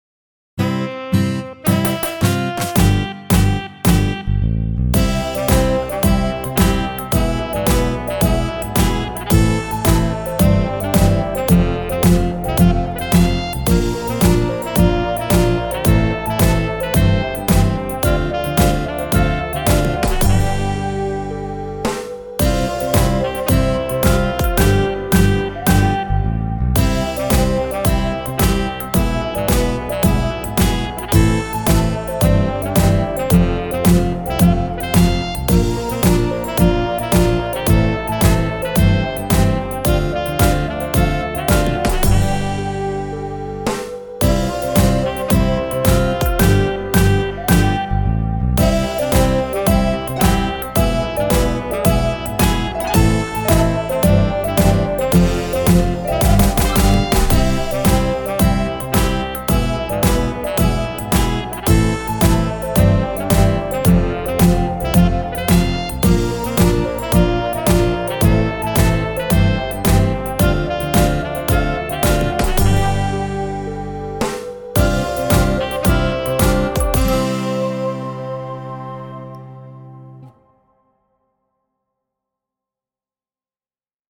BPM: 110
Open / Download (Full・Inst)